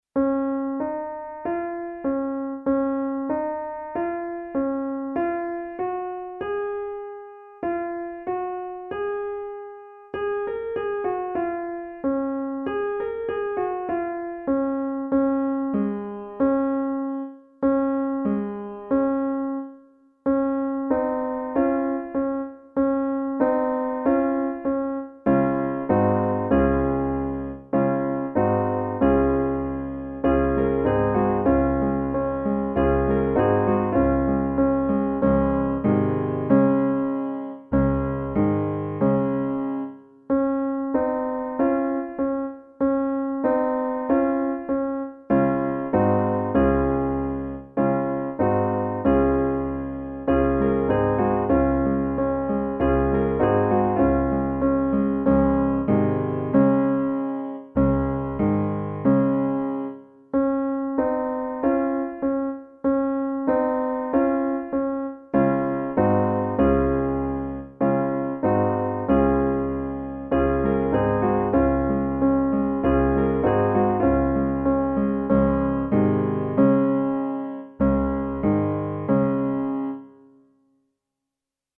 Hier kommt die Klavierbegleitung zu "Bruder Jakob" zum Mitsingen
Lied-BruderJakob-Begleitung.mp3